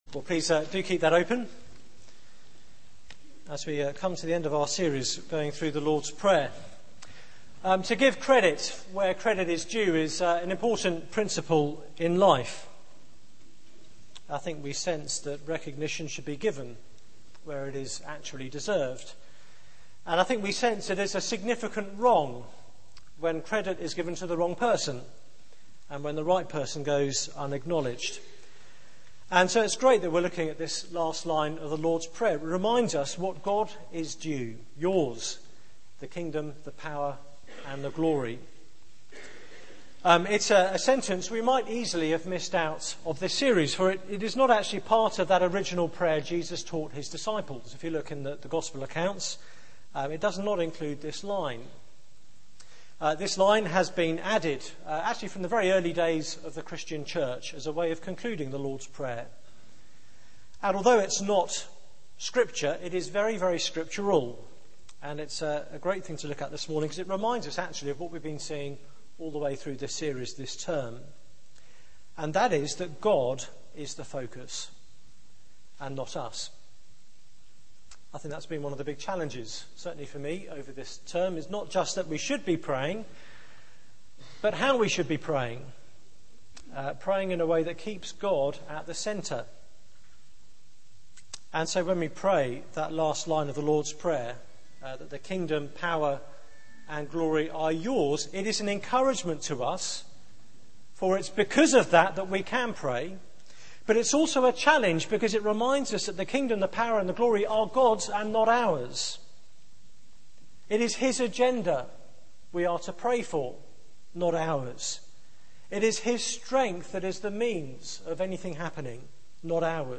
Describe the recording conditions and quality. Media for 9:15am Service on Sun 07th Nov 2010